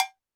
Drums_K4(33).wav